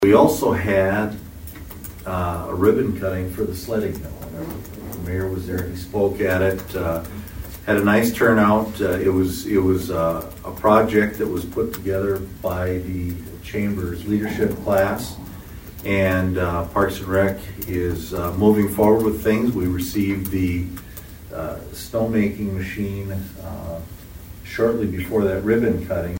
ABERDEEN, S.D.(HubCityRadio)- Toward the end of Monday’s City Council meeting, Aberdeen City Manager Robin Bobzien addressed couple issues dealing with the city of Aberdeen.